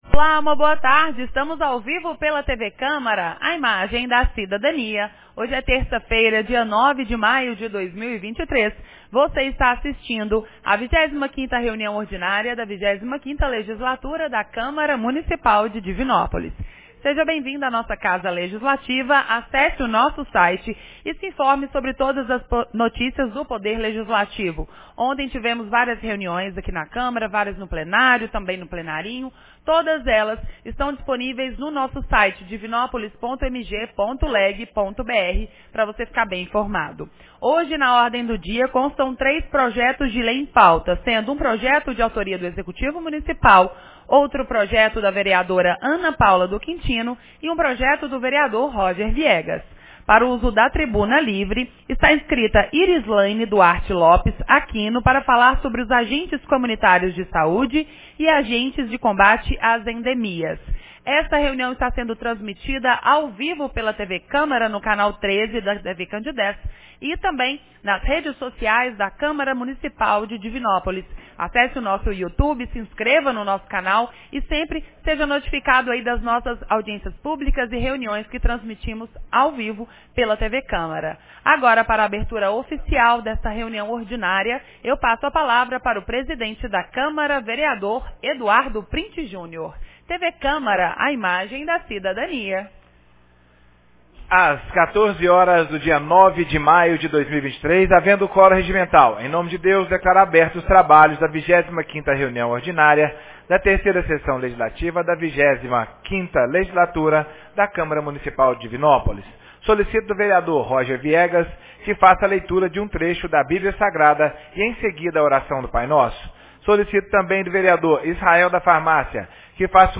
25ª Reunião Ordinária 09 de maio de 2023